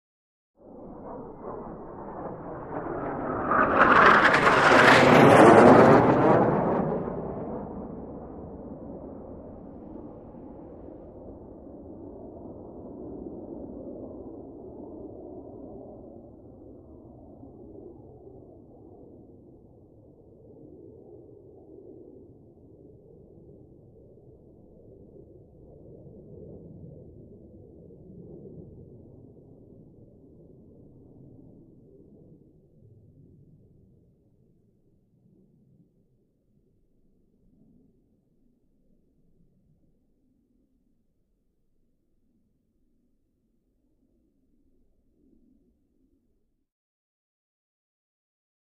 F-5: By Low; Powerful, Raspy F-5 Flyby And Away. Medium To Distant Perspective. Jet.